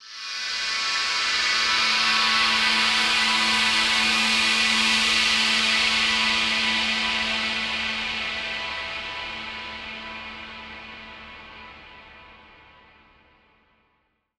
SaS_HiFilterPad05-A.wav